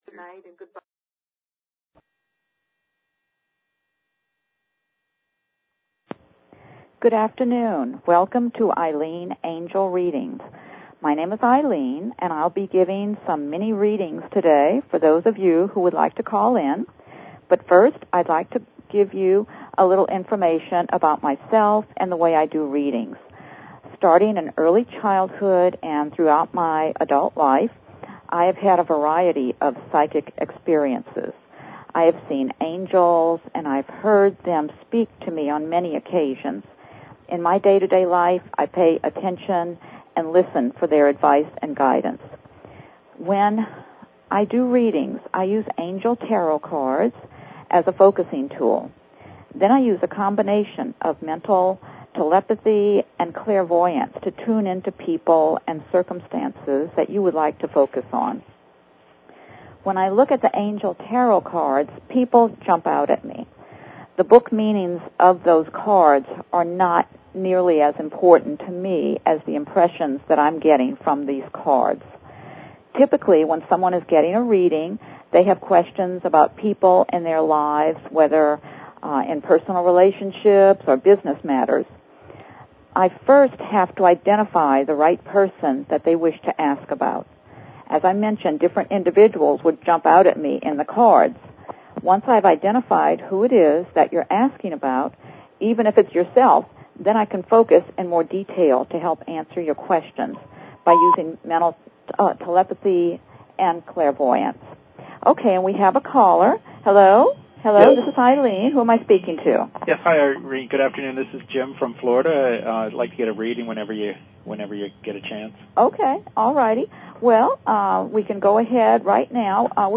Clairvoyant Angel Card Readings